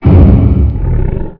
bangdoor1.wav